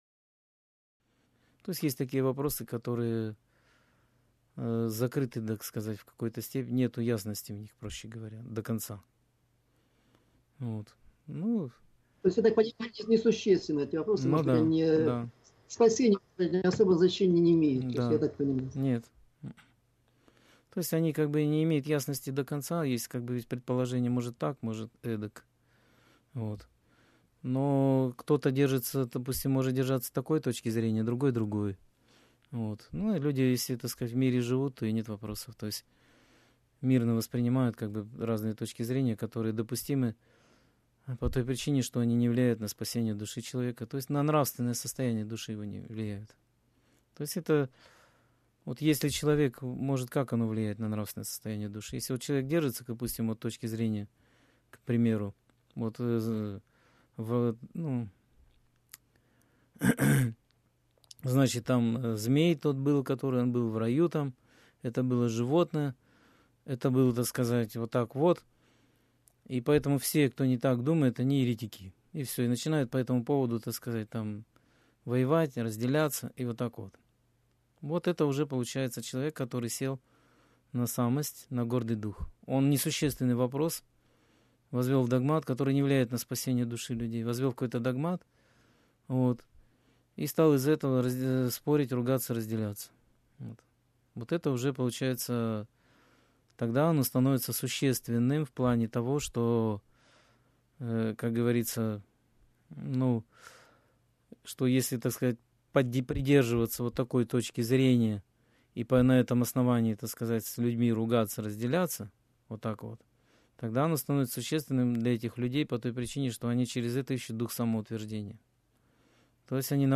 Скайп-беседа 27.05.2017